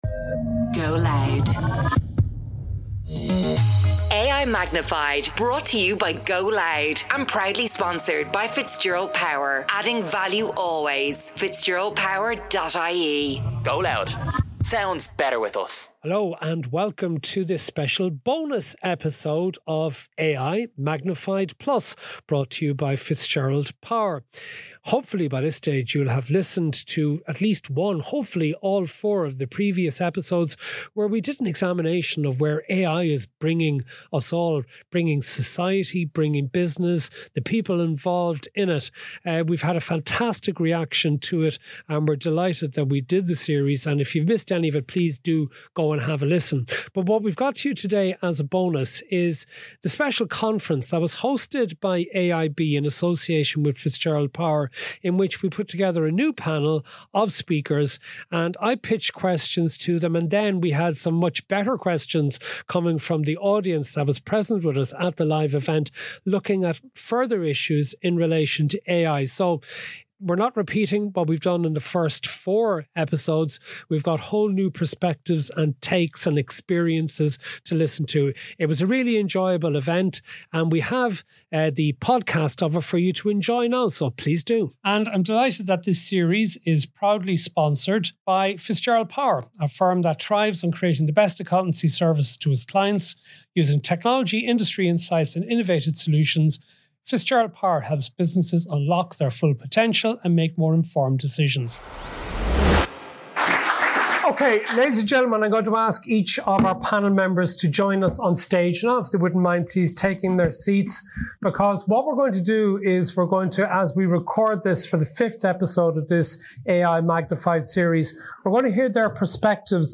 For the fifth and special bonus episode of this AI Magnified series, we're going live from A.I.B on Molesworth St to hear from an expert panel and their perspectives on leveraging AI to drive …
magnified_plus_live_edit_e6f0e6ad_normal.mp3